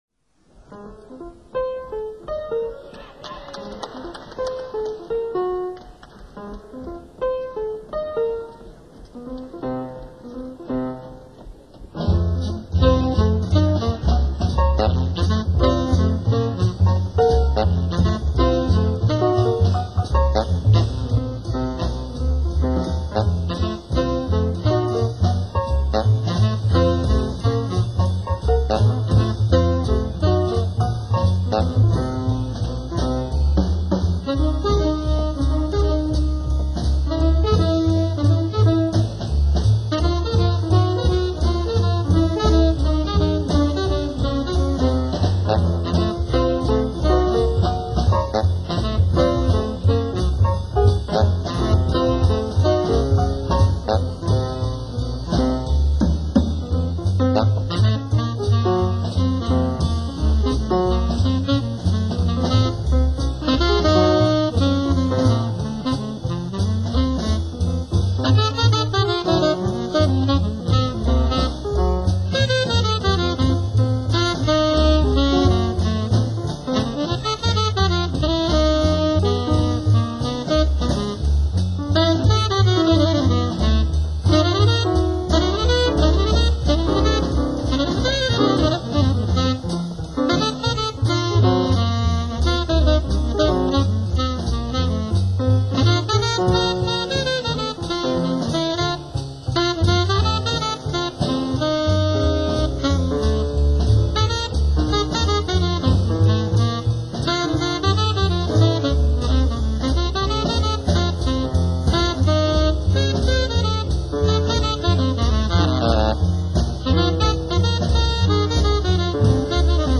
tenor Sax
bass
drums